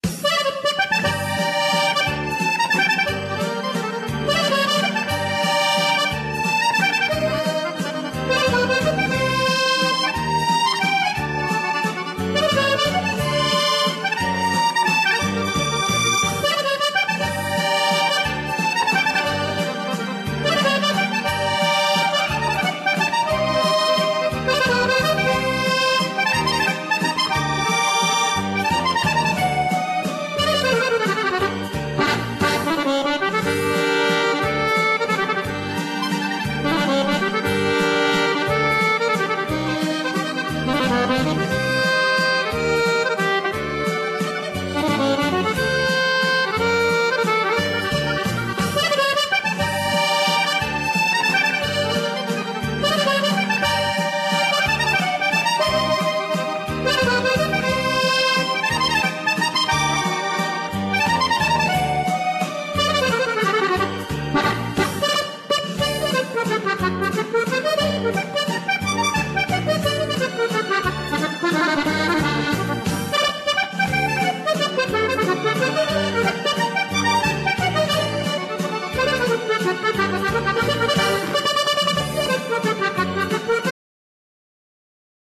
Genere : Folk